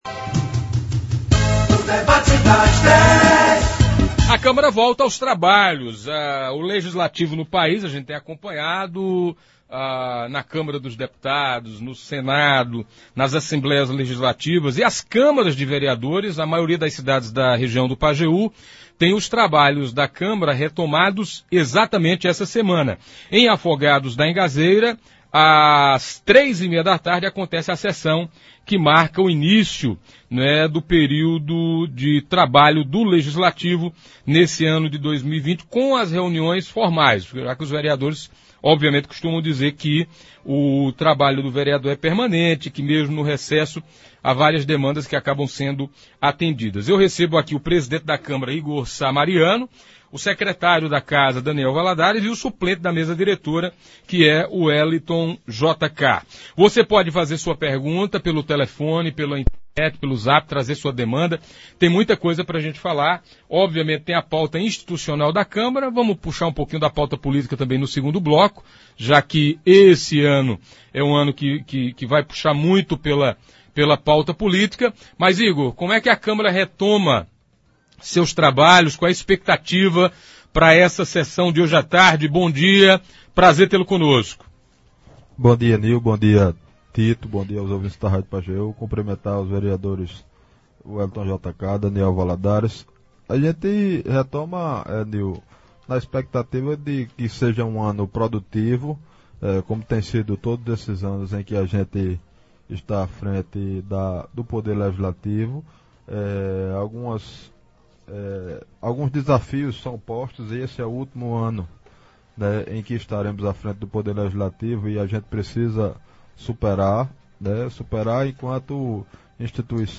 No Debate das Dez da Rádio Pajeú desta terça-feira (04.02), os vereadores, Igor Sá Mariano, presidente da Câmara, Raimundo Lima, Daniel Valadares e Wellington JK, falaram sobre a abertura do ano legislativo, que aconteceu hoje com sessão às 16h.